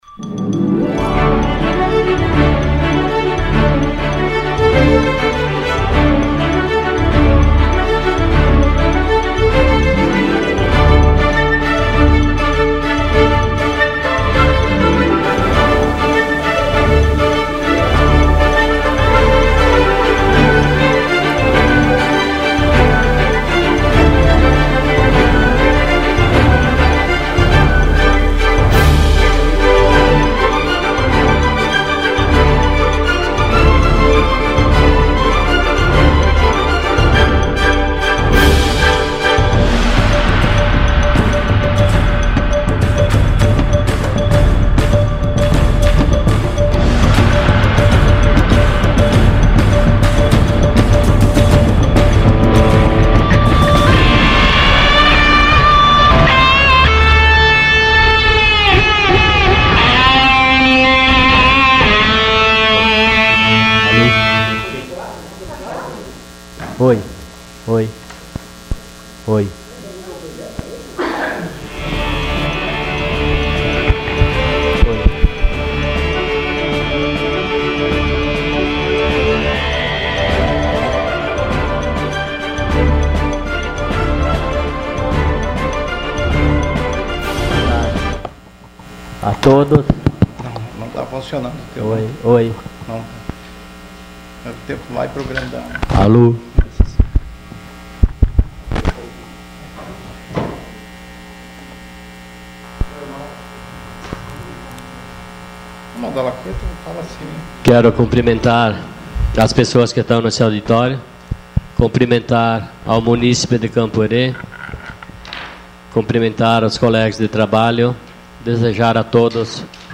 Sessão Ordinária dia 17 de outubro de 2016.